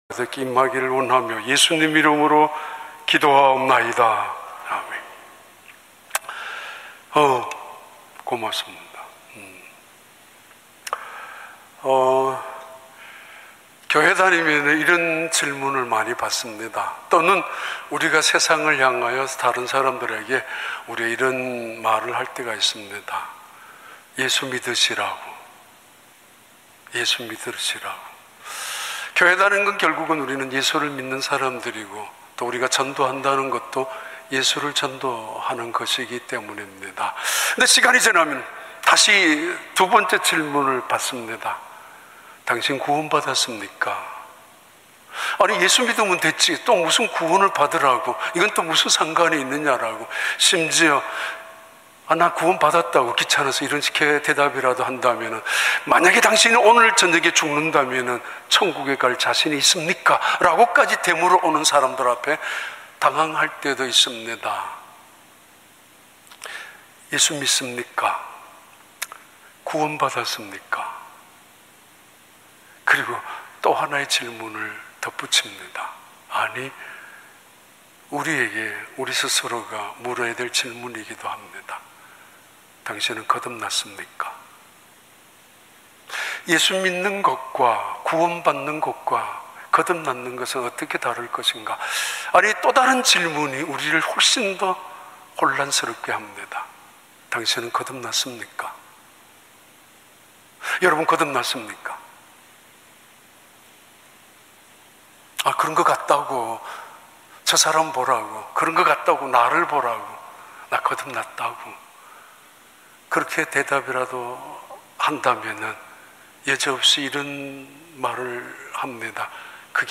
2022년 7월 3일 주일 3부 예배